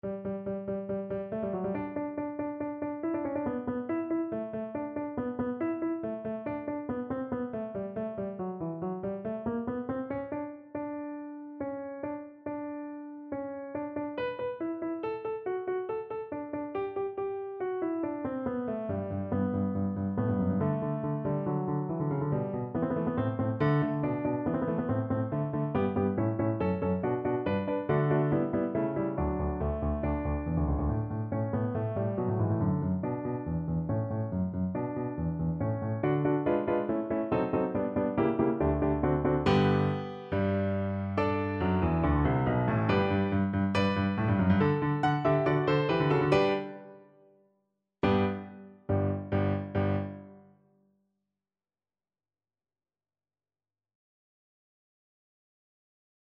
= 140 Allegro (View more music marked Allegro)
4/4 (View more 4/4 Music)
Classical (View more Classical Cello Music)